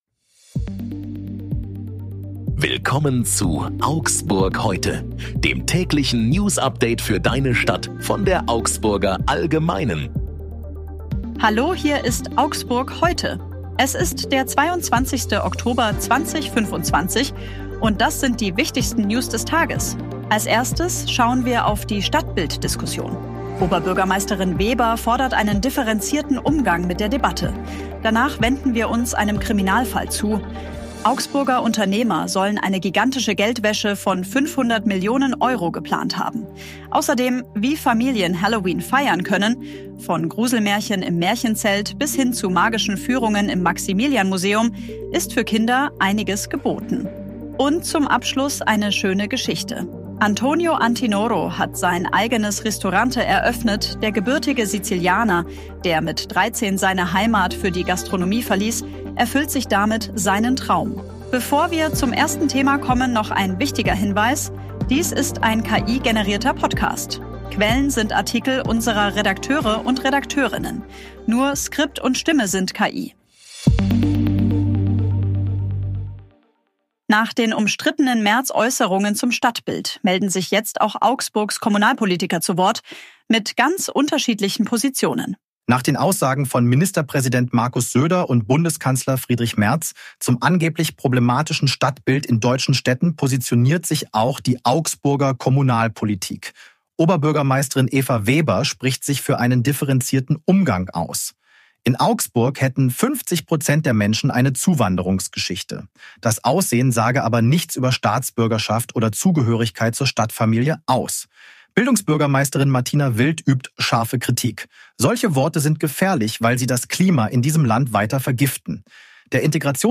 Hier ist wieder das tägliche Newsupdate für deine Stadt.
Skript und Stimme sind KI.